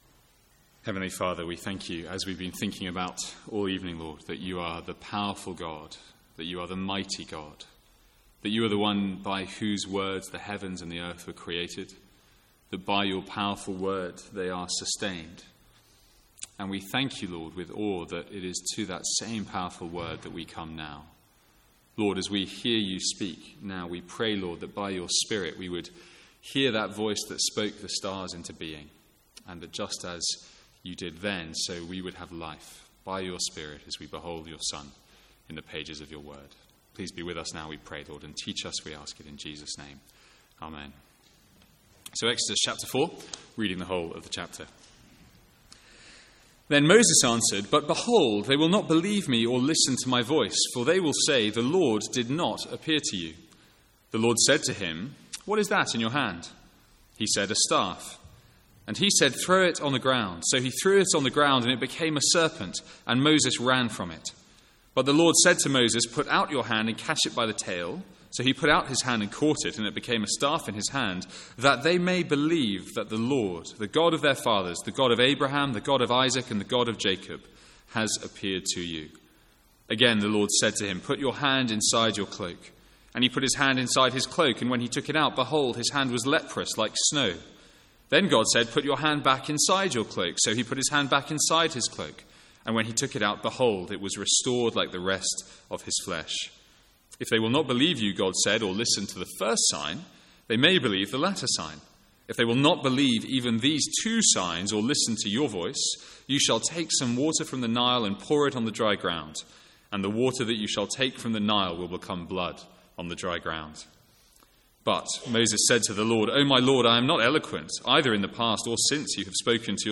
Sermons | St Andrews Free Church
From the Sunday evening series in Exodus.